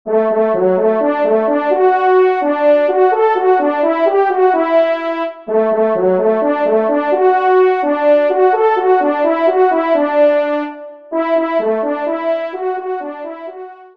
TON SIMPLE  :
SOLO